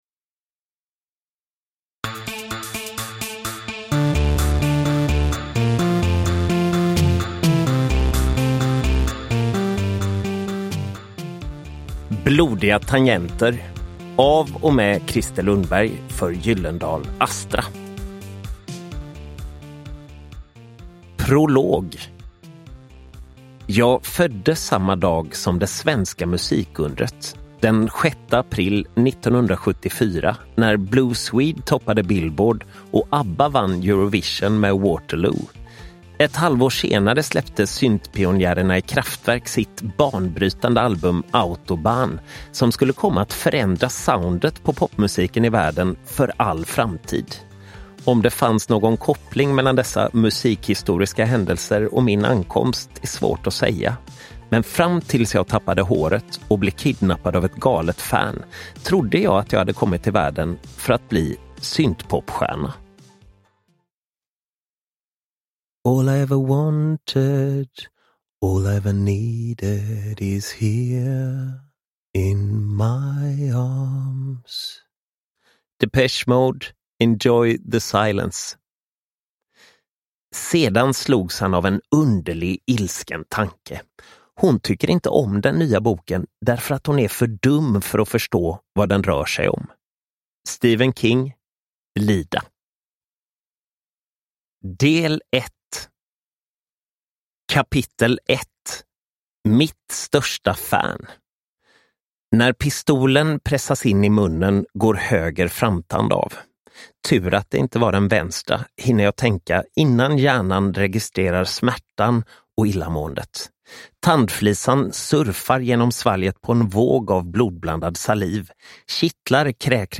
Blodiga tangenter – En stor roman om ett litet band – Ljudbok